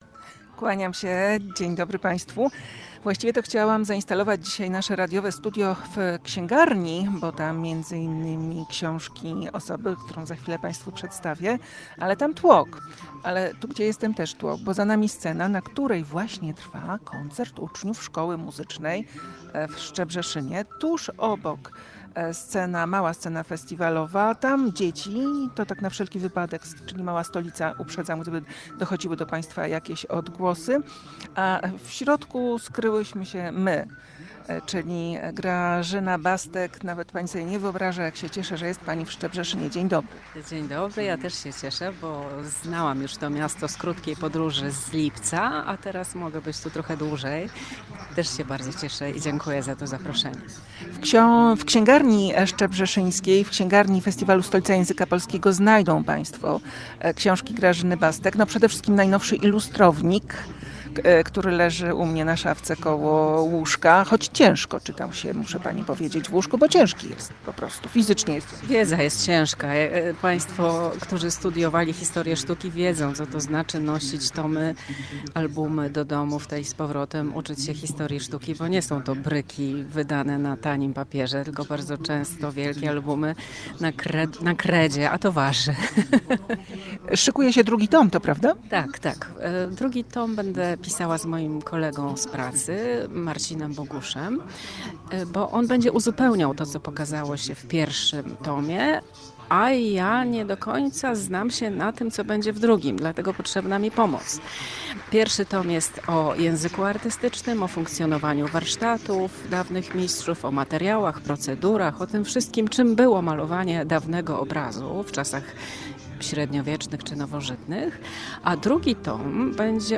W Szczebrzeszynie trwa Festiwal Stolica Języka Polskiego.